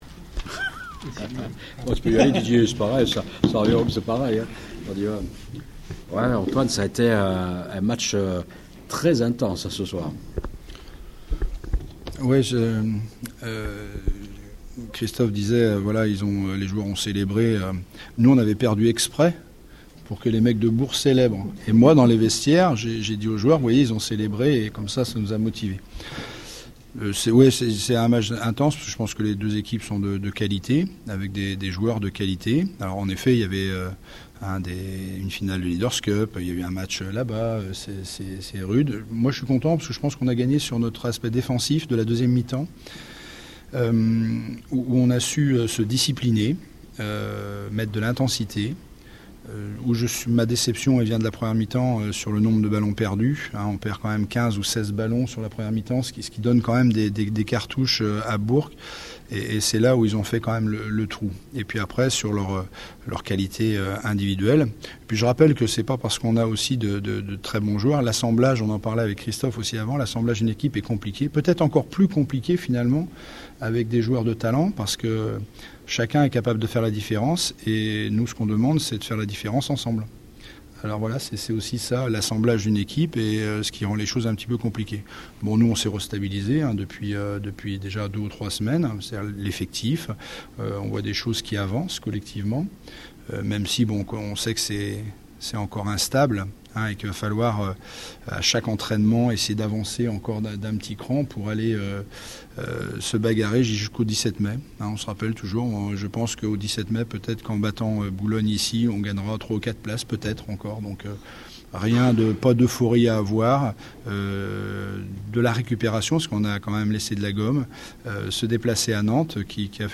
Interviews d'après match - JL Bourg Basket
Découvrez les interviews d’après match au micro de radioscoop.